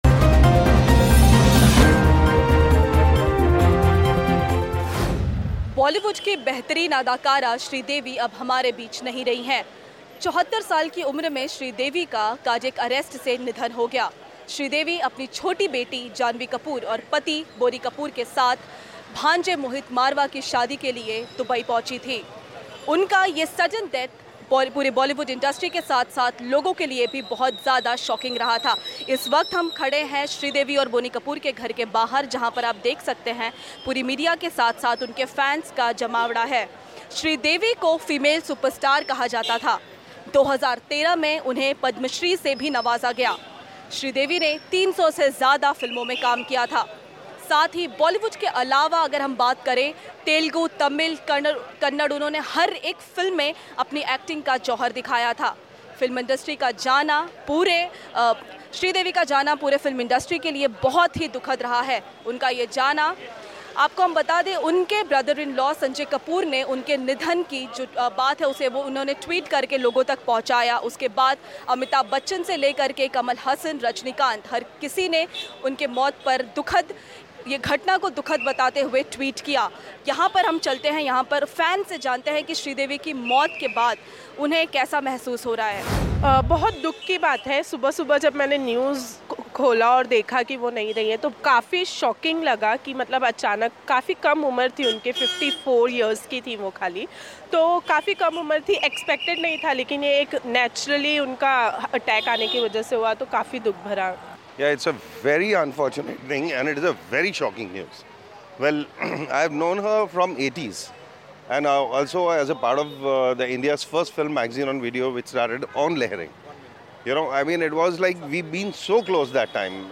रूप की रानी श्रीदेवी की मौत से सभी को गहरा सदमा लगा है. HW न्यूज़ ने उनके चाहने वालो से की बात.